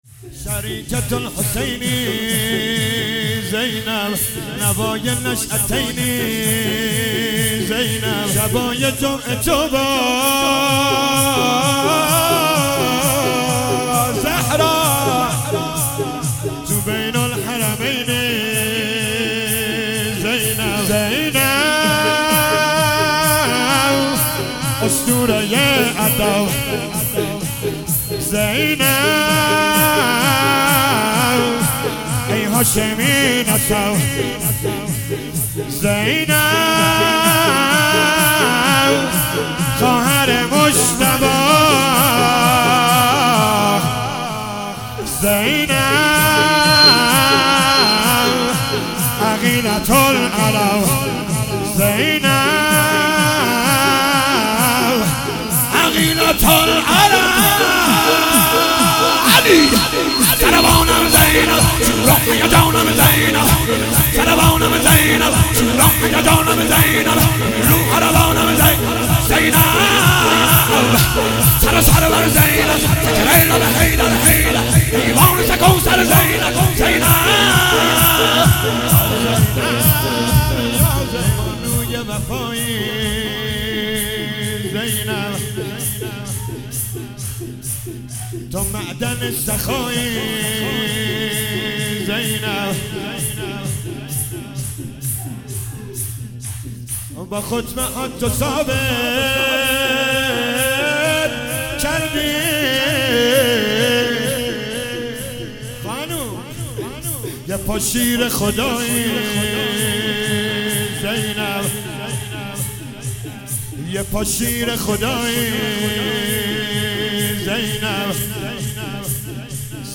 مداحی
شهادت امام صادق(ع) هیئت جوانان حضرت ابوالفضل العباس(ع) تهران